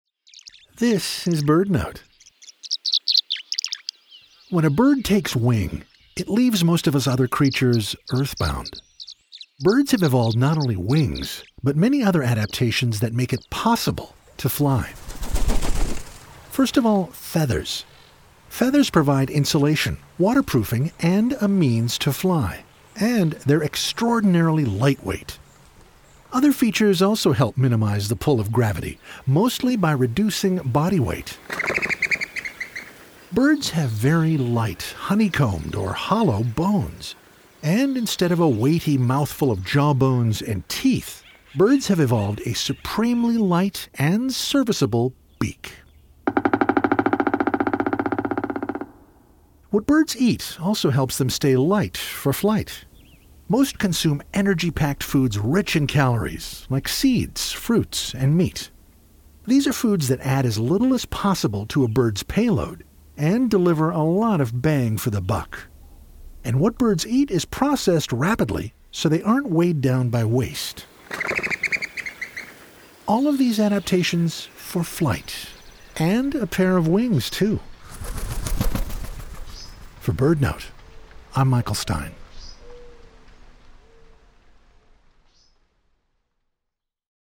BirdNote is sponsored locally by Chirp Nature Center and airs live everyday at 4 p.m. on KBHR 93.3 FM.